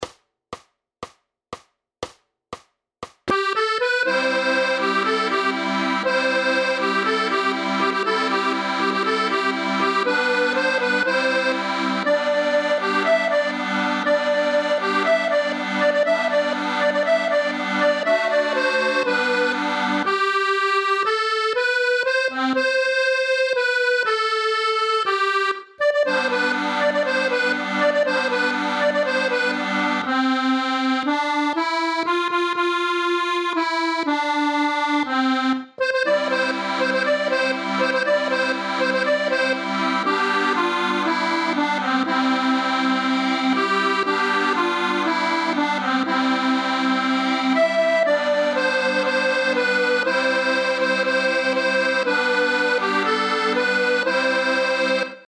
Noty na akordeon.
Hudební žánr Klasický